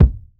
Boom-Bap Kick 70.wav